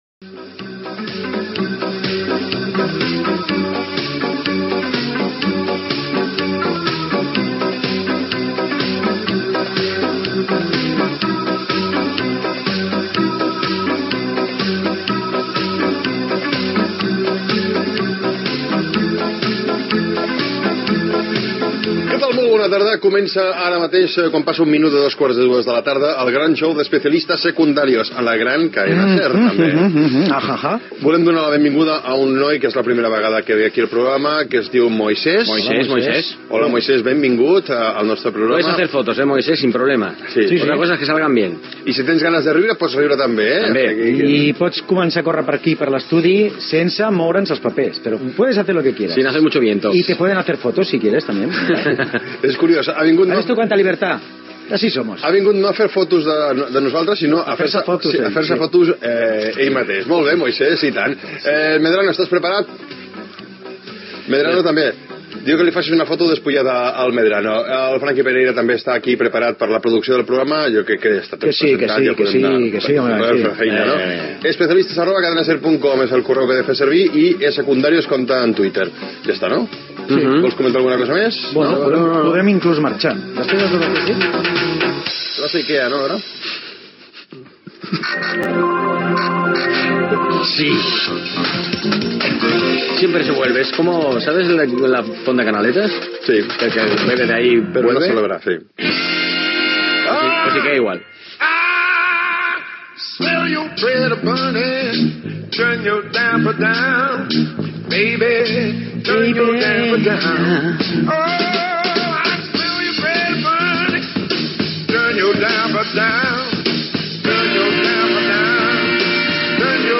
Sintonia, presentació, equip, adreça electrònica de contacte amb el programa, comentaris sobre els programes dels divendres
Gènere radiofònic Entreteniment